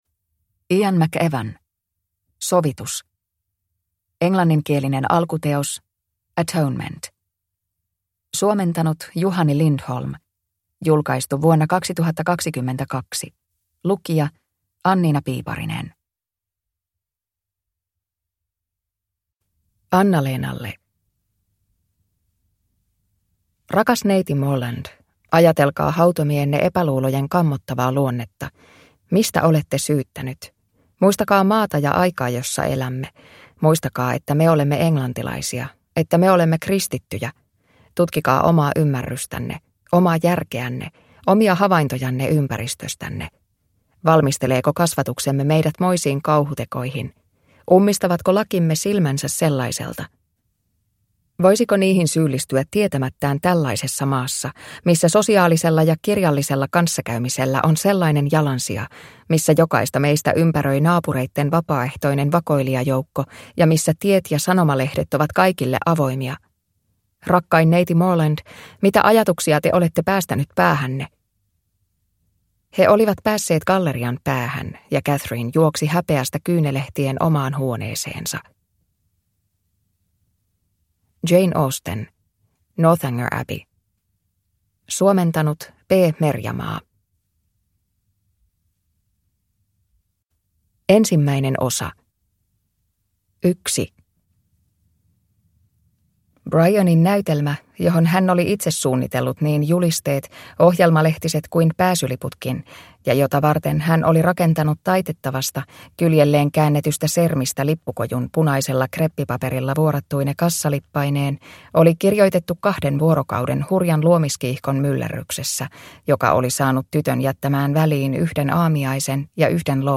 Sovitus – Ljudbok – Laddas ner